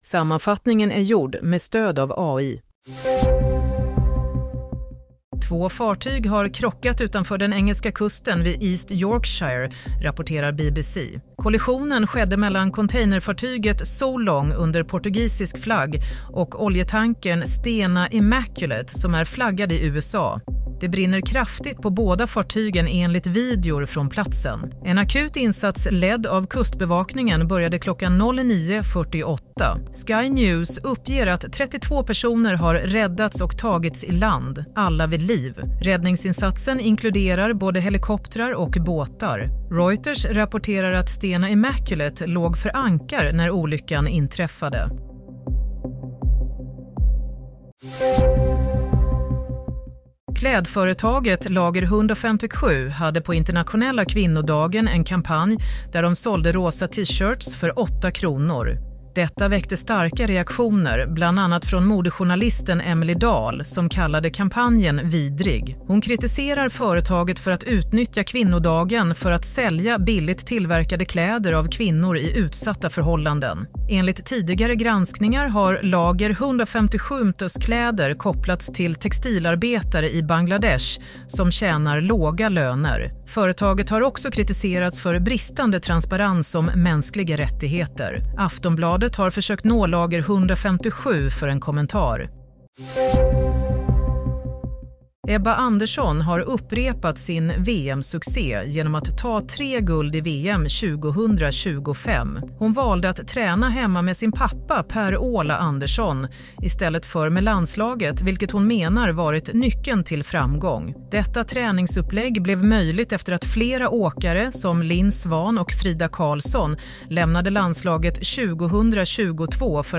Sammanfattningen av följande nyheter är gjord med hjälp av AI. – Larm om fartygskrock nära England – Klädkedjan sålde t-shirts för 8 kronor – på kvinnodagen – Nyckeln bakom nya VM-succén Broadcast on: 10 Mar 2025